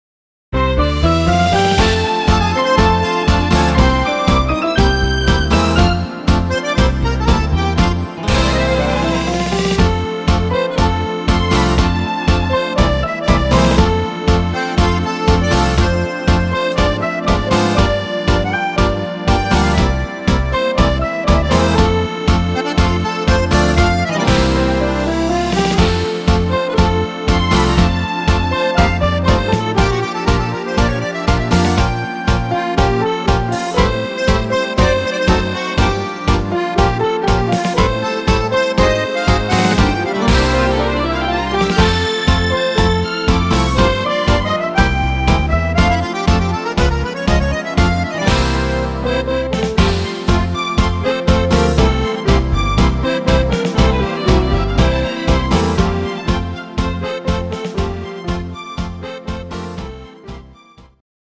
Rhythmus  Tango
Art  Deutsch, Schlager 60er